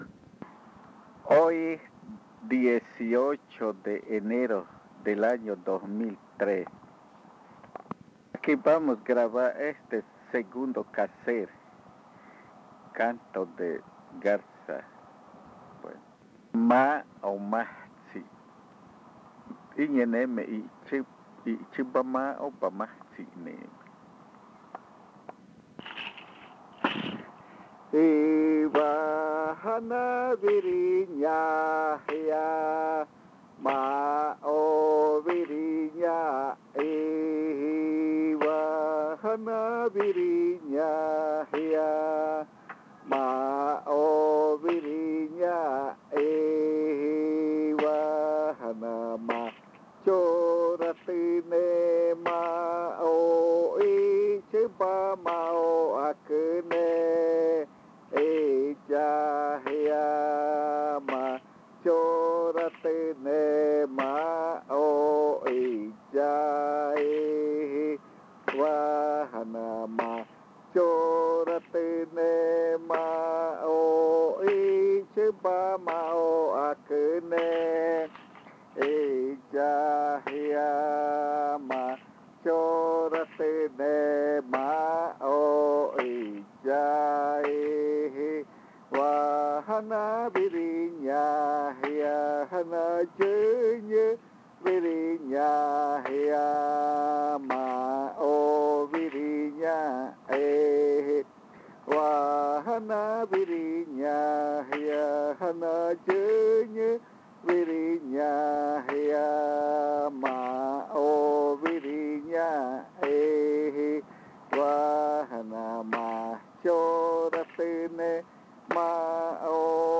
I’chʉba majtsi pertenece al segundo grupo de bailes miraña; es decir, a los bailes originados con Ayvejʉ I’chʉba, hijo del Creador Piiveebe, los cuales tratan sobre agradecimiento o alabanza. El audio contiene los lados A y B del casete.